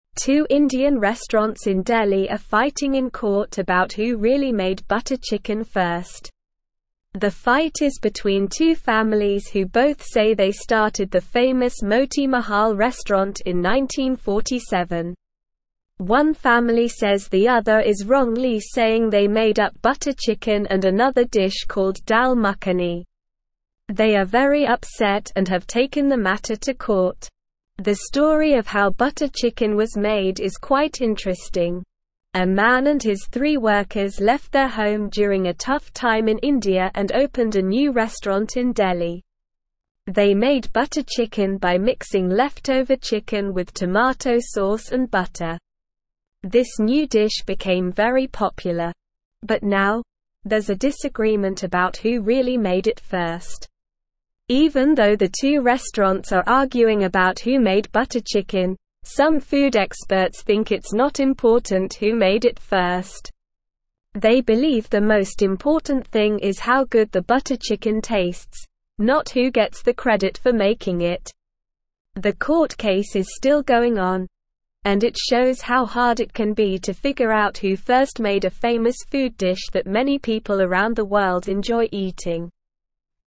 Slow
English-Newsroom-Lower-Intermediate-SLOW-Reading-Butter-Chicken-Battle-Who-Made-It-First.mp3